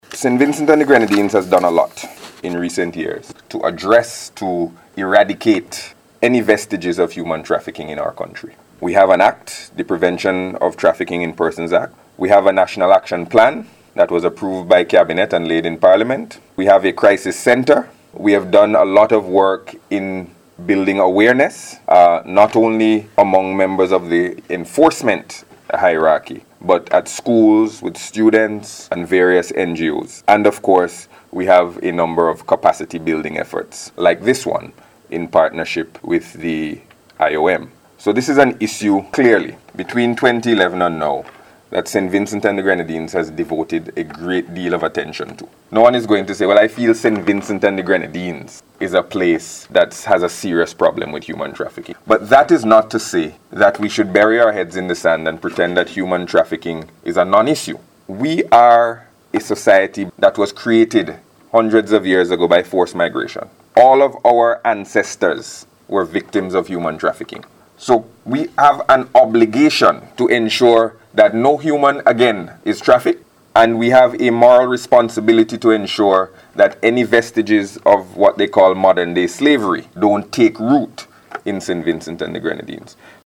Speaking at this morning’s opening ceremony Minister of Foreign Affairs Camillo Gonsalves said between 2011 and 2014 this country devoted a great deal of attention to human trafficking through a number of projects and initiatives.